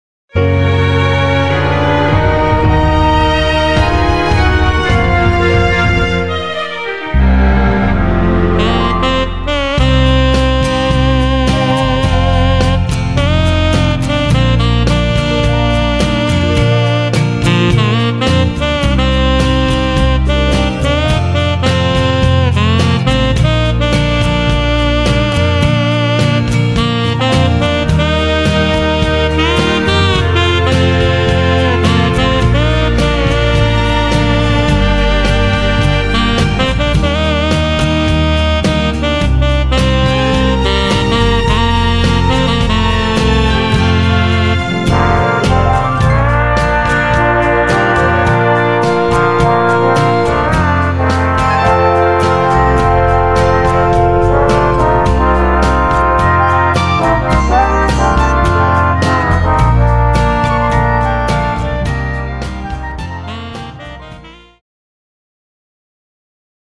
Cued Sample
Foxtrot, Phase 4